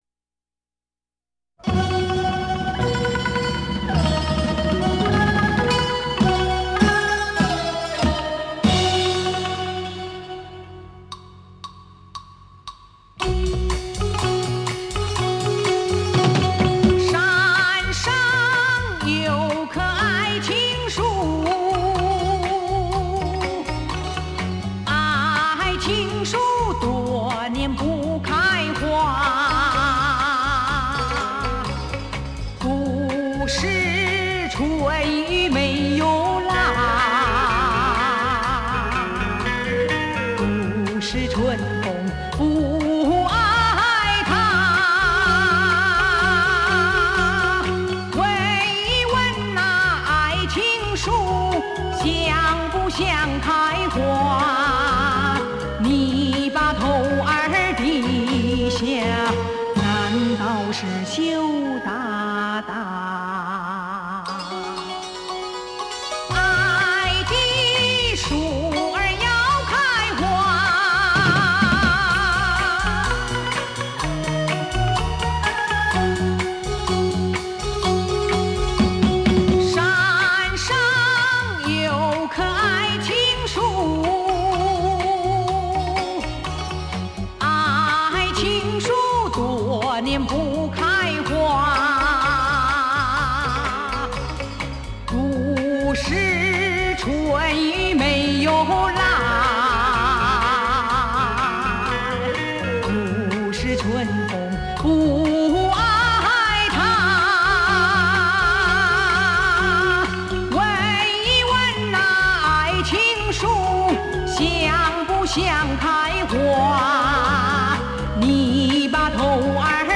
本唱片系列采用近30年间 最值得珍藏之原唱老母带所录制 弥足珍贵！